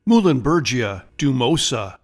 Pronounciation:
Muh-len-BER-gee-a dew-MOW-sa